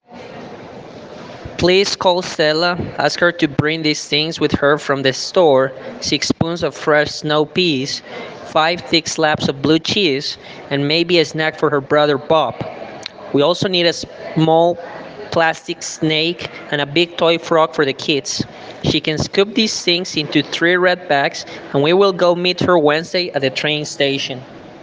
A Colombian Accent
El orador es nativo de Medellín, Antioquía, Colombia, y se observa un moderado acento en su pronunciación.